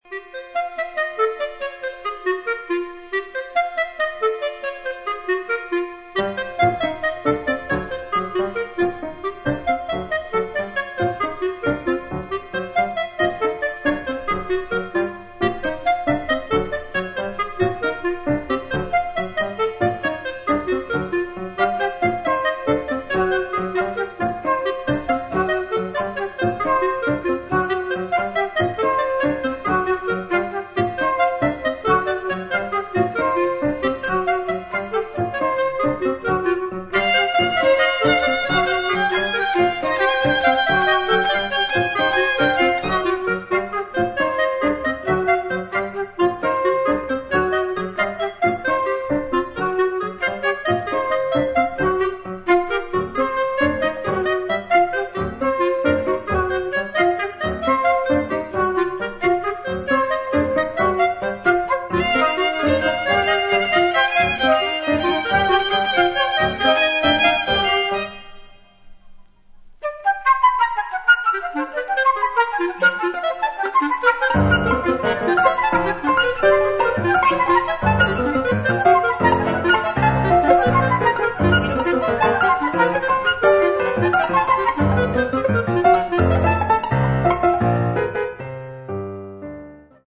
A2b Scherzo - Allegretto Con Brio